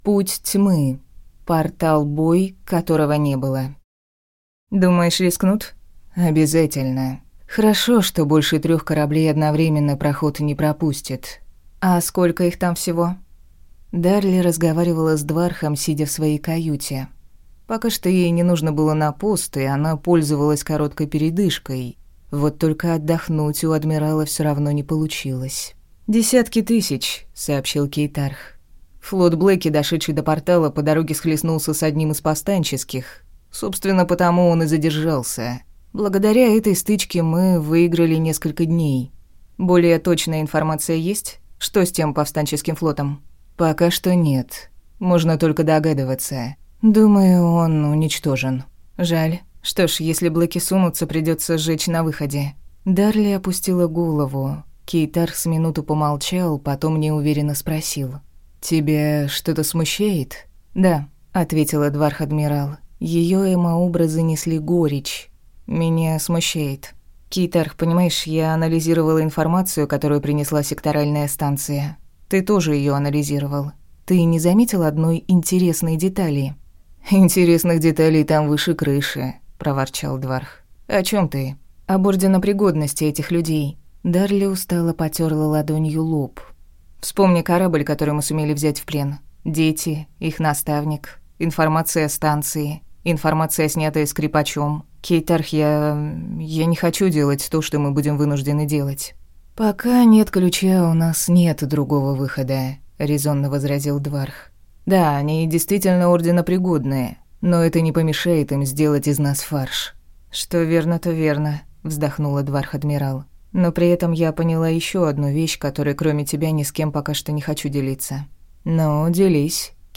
Аудиокнига Ночь черных звезд | Библиотека аудиокниг